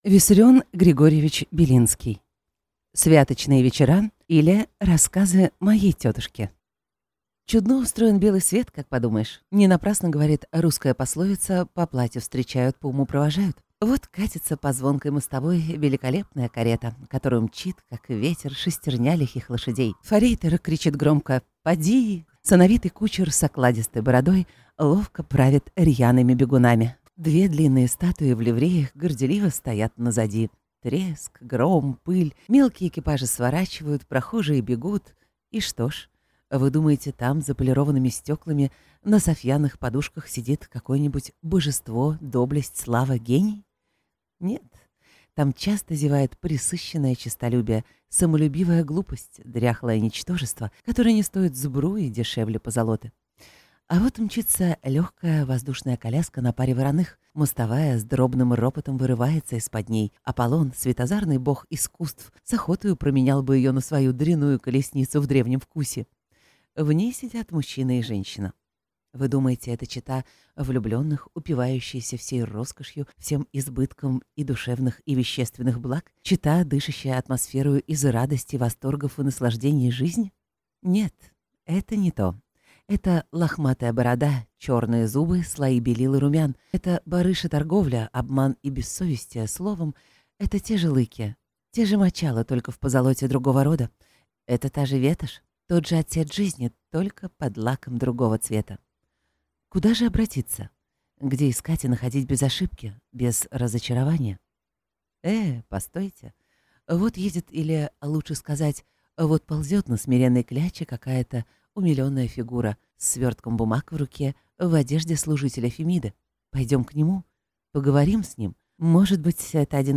Аудиокнига Святочные вечера, или Рассказы моей тетушки | Библиотека аудиокниг